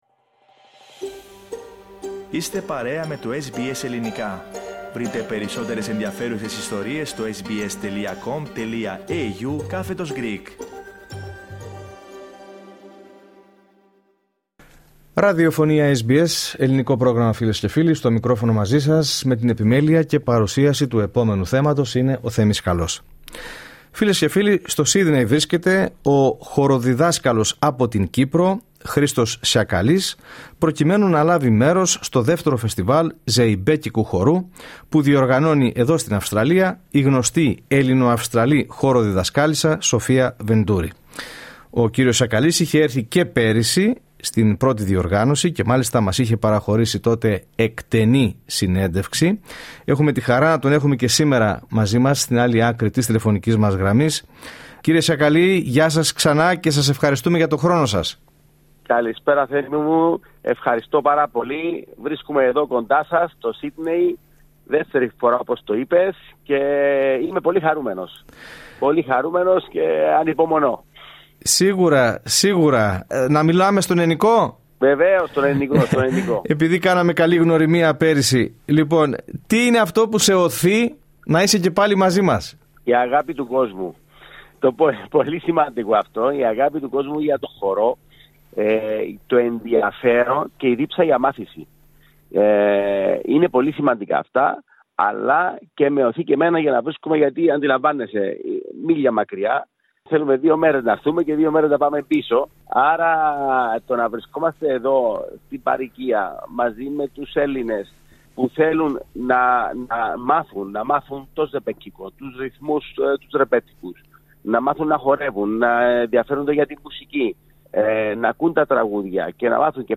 Σε συνέντευξη που παραχώρησε στο Πρόγραμμά μας, SBS Greek